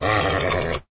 horse
angry1.ogg